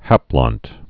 (hăplŏnt)